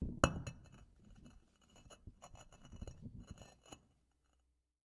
mobs_stone.ogg